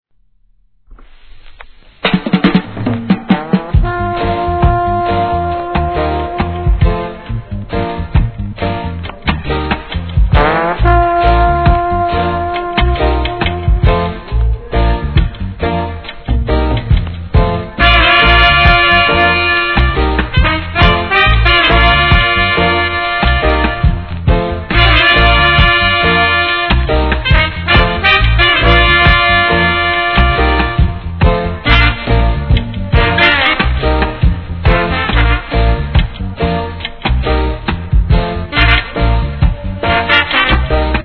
7inch
REGGAE